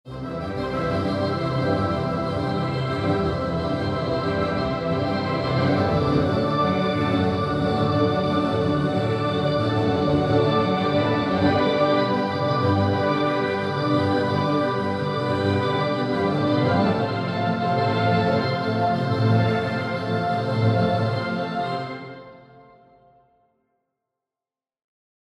Hier in Kombination mit dem Granular-3D-Echo GRM Space Grain: